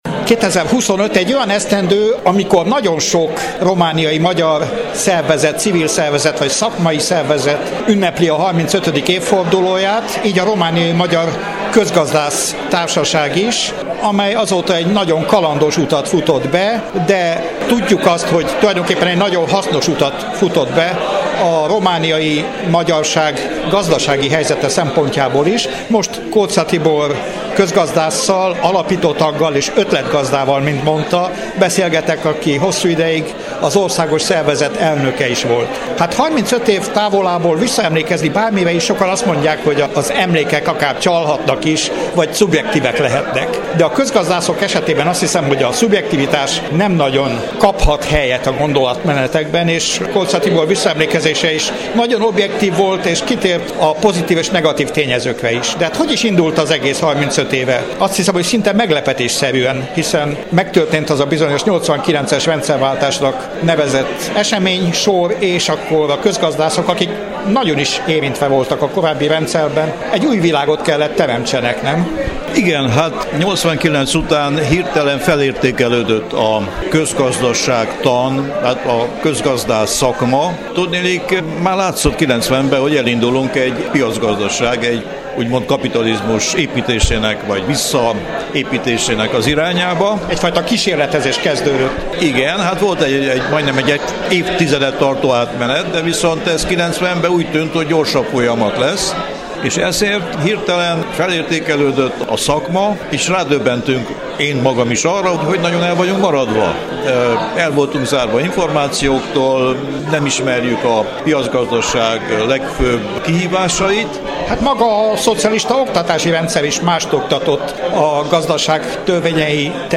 Az eseményt követően kértünk mikrofon elé két résztvevőt.